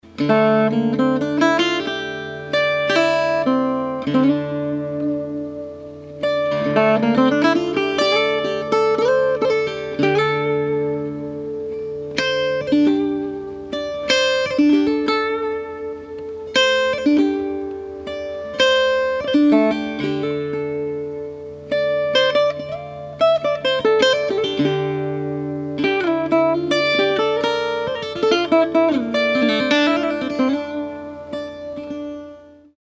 The brass saddle is angled to buzz against the strings.